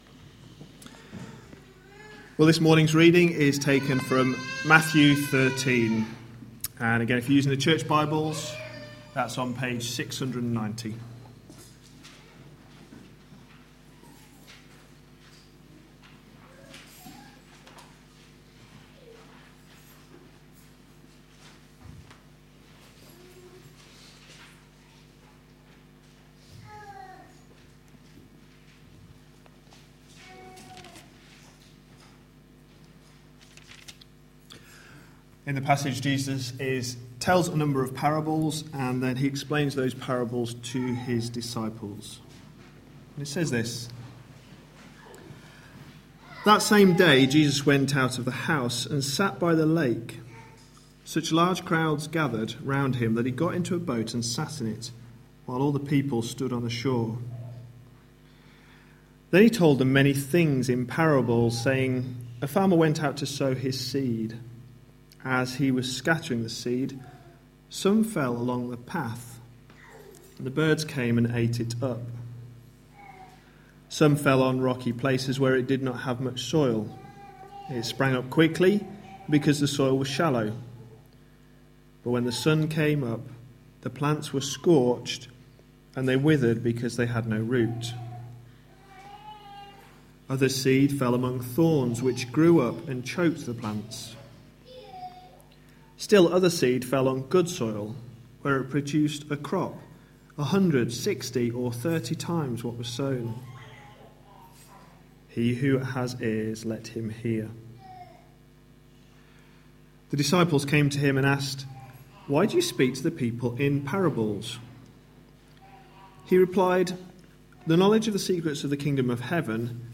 A sermon preached on 18th May, 2014, as part of our Changing the way you think series.